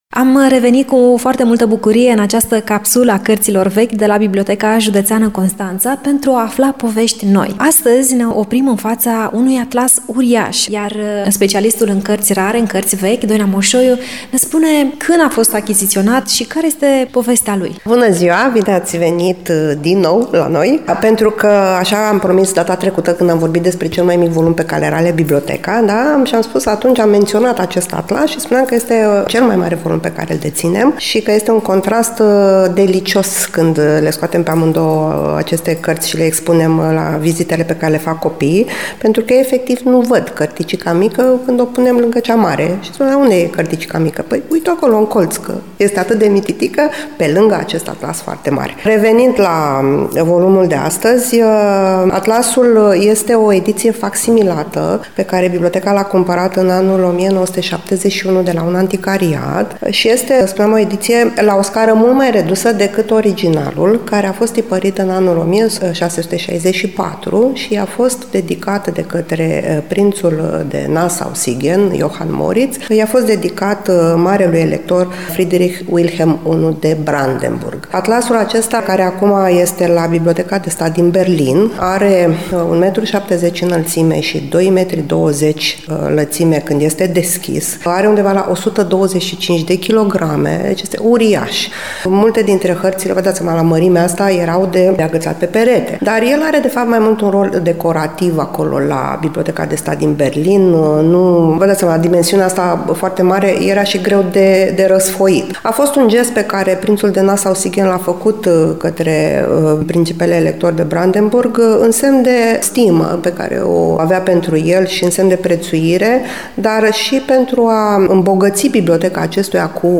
La Biblioteca Județeană, în sala de cărți rare și vechi ne oprim astăzi, la Piesa de Patrimoniu, să ne minunăm de un volum care cântărește mai bine de 20 de kilograme, are coperțile învelite în piele de vițel și conține hărți ale globului pământesc, bine meșteșugite.